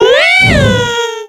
Cri d'Hélionceau dans Pokémon X et Y.